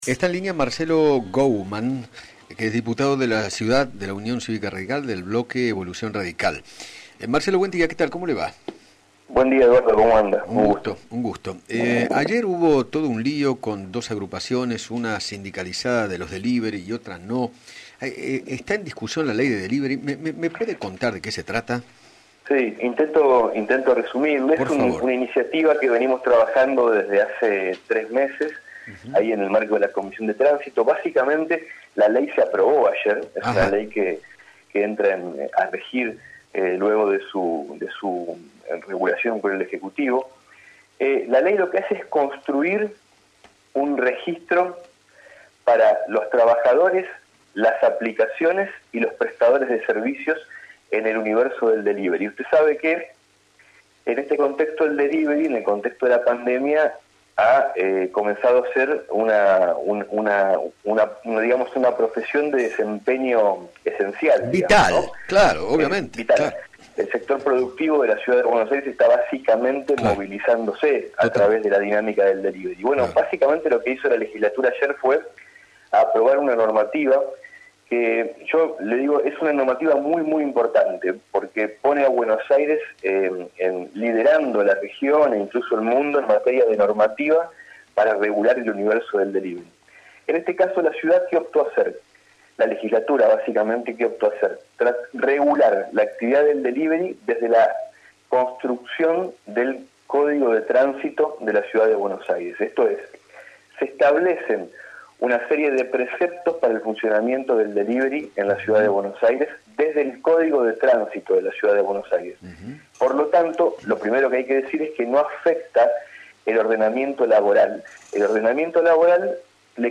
Marcelo Guouman, Legislador por la UCR Capital, dialogó con Eduardo Feinmann sobre la ley que regula la actividad de los repartidores y app de delivery en la ciudad de Buenos Aires, que obliga a todos ellos a constituir domicilio en CABA, contar con un seguro laboral y los elementos de trabajo necesarios. Además, no le podrán cobrar más del 20% de comisión a los comercios.